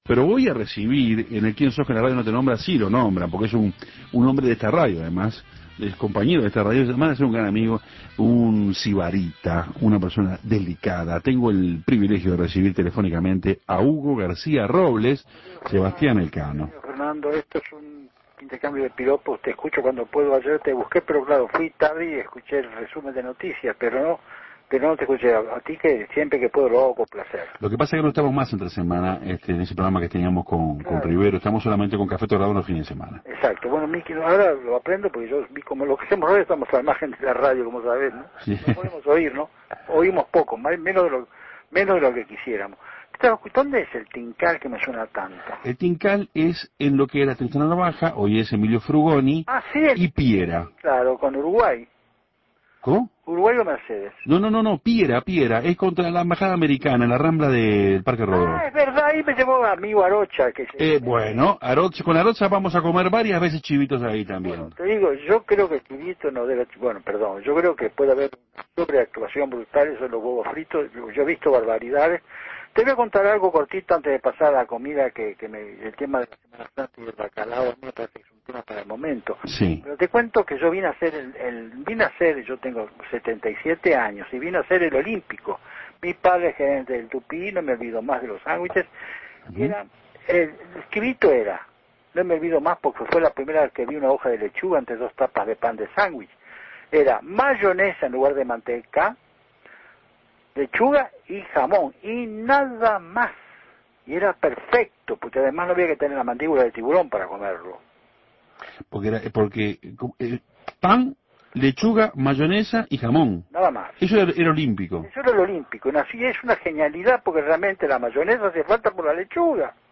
fue entrevistado en Café Torrado en donde habló de uno de sus placeres: el buen comer. En el comienzo de la Semana Santa, se refirió al menú especial que rige esos días, especialmente, al bacalao.